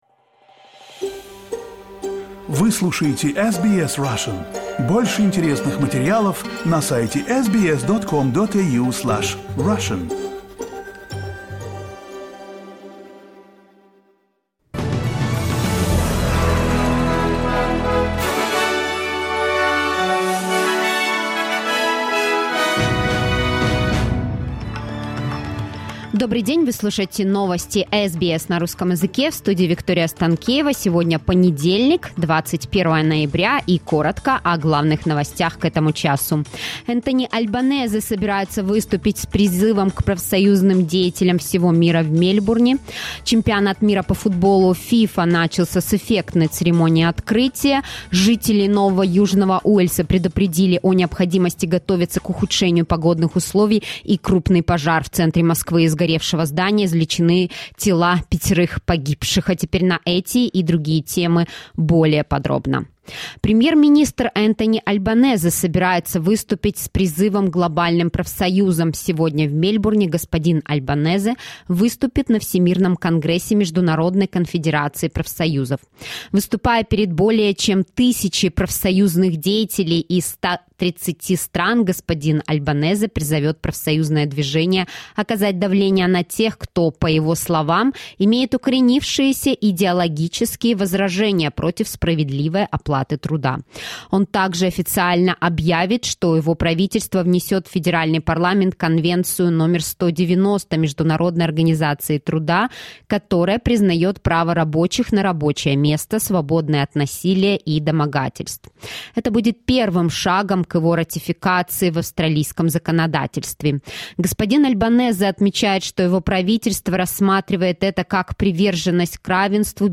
SBS News in Russian - 21.11.2022 | SBS Russian